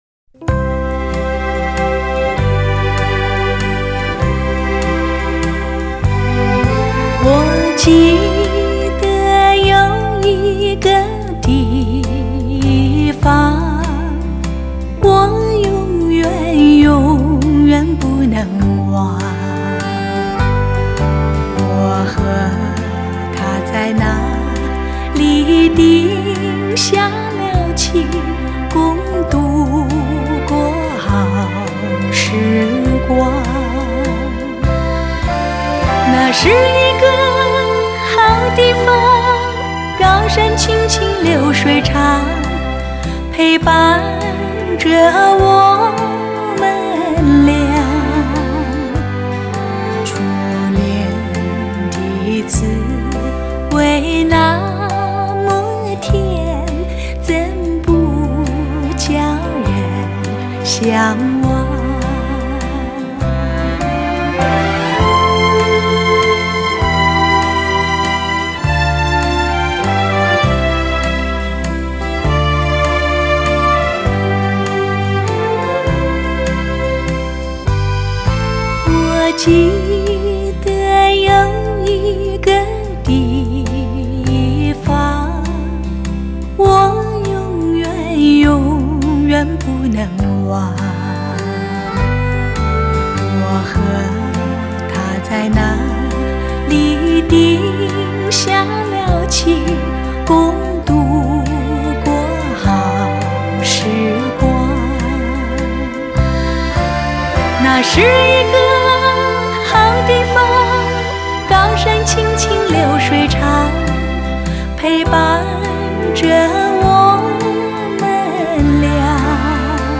天籁之音 天使下凡 永远的一代柔情甜歌皇后